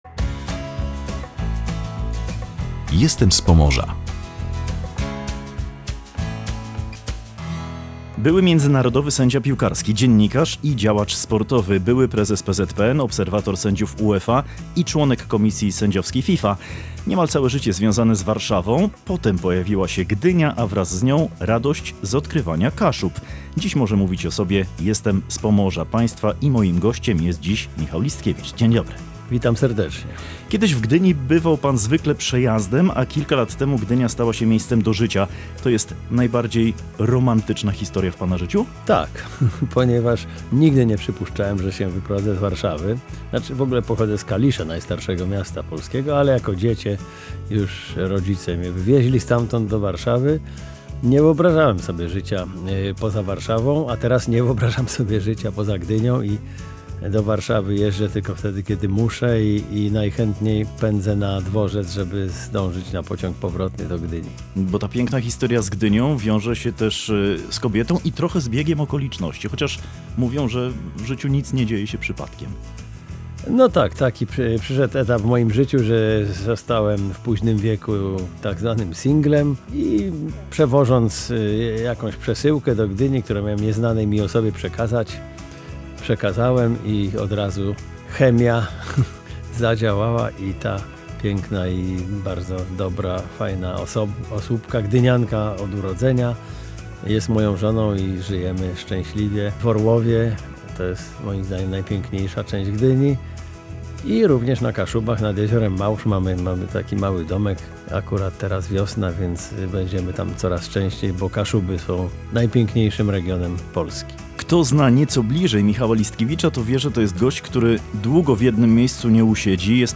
Gościem audycji „Jestem z Pomorza” jest Michał Listkiewicz.